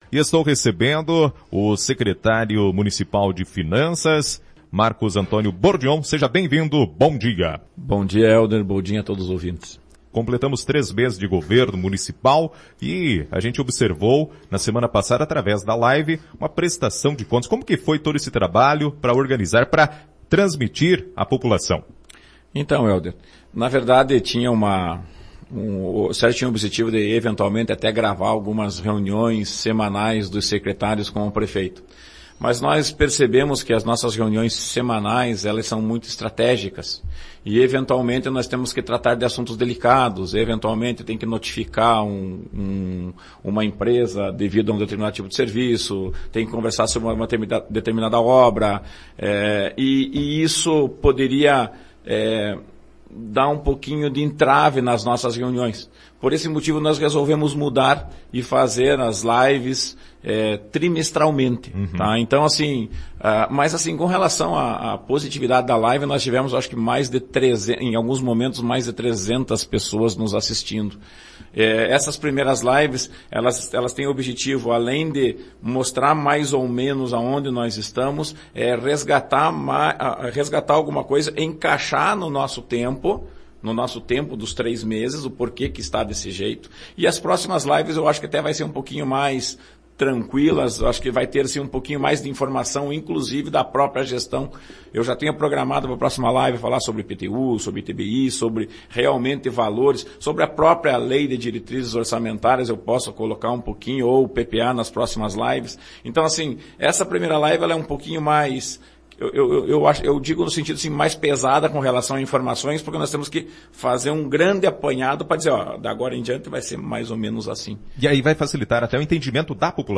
Nesta manhã (14) o Secretário de Finanças Marcos Antonio Bordinhão esteve ao vivo no Bom Dia Palmeira, na Rádio Ipiranga. Durante a entrevista foi abordado sobre a iniciativa da live de prestação de contas que foi realizada na quinta-feira dia 08 de abril e o Plano Plurianual (PPA) e a Lei de Diretrizes Orçamentárias (LDO).
Ouça a entrevista na íntegra e saiba mais sobre PPA, LDO e LOA.